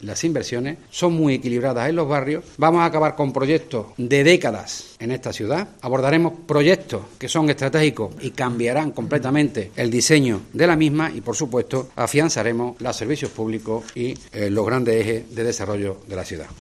Escucha a Salvador Fuentes, delegado de Hacienda, sobre las inversiones del presupuesto aprobado para Córdoba